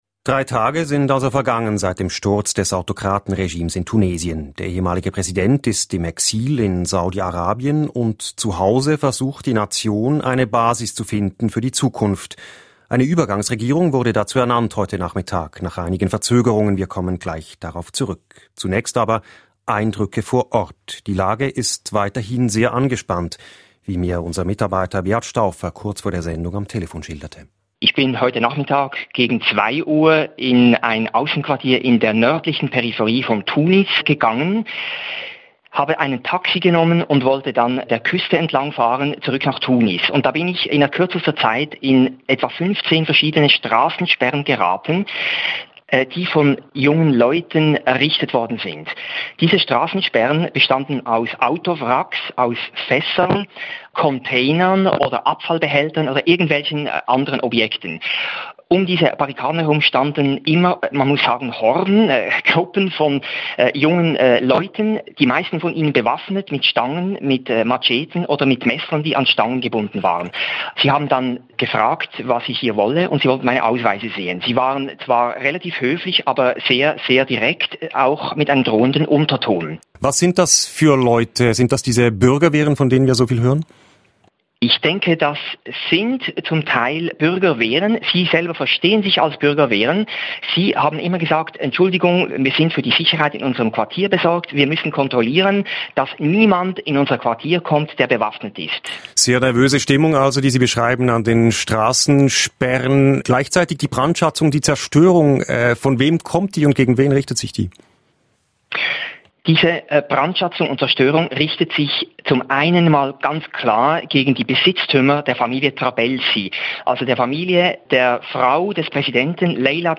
Reaktionen von Tunesierinnen und Tunesier im Exil